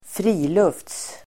Uttal: [²fr'i:lufts-]